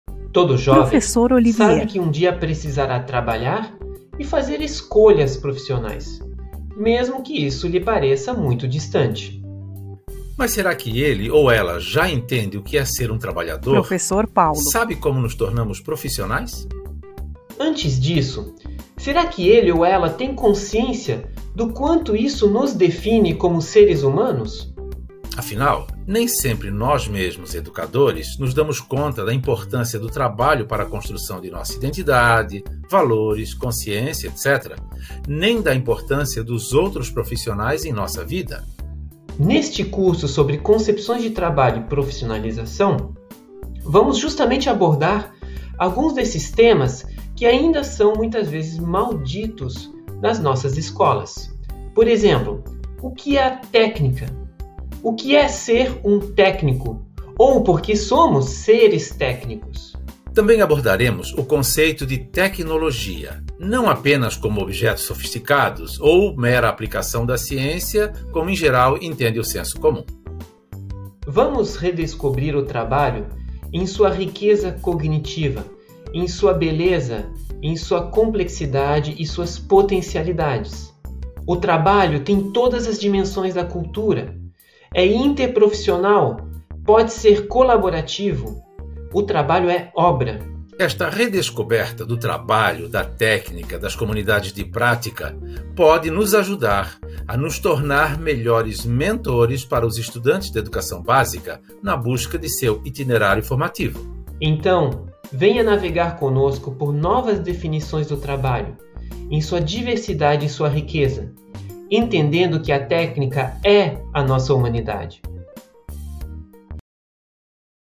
Áudio do vídeo com AD
boas-vindas_COM_AD.mp3